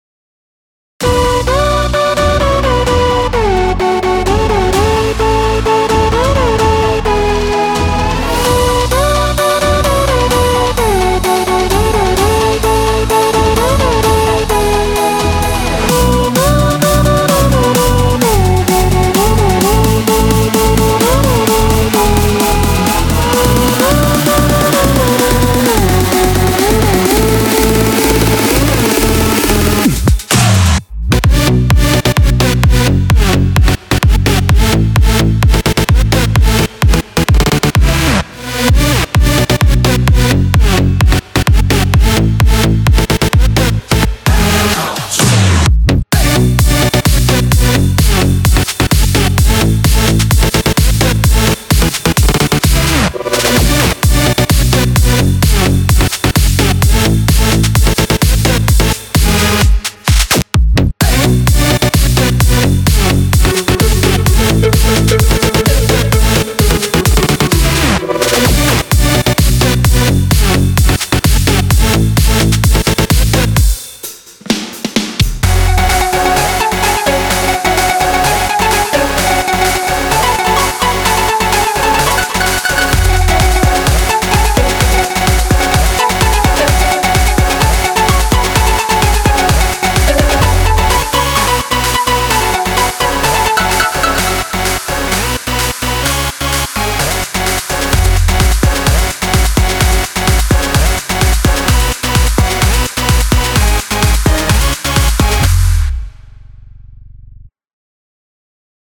פתיח פריילך הורה דרבוקות ודאנס קטרון HORA.MP3 kKETRON X1.MP3 PATIACH.MP3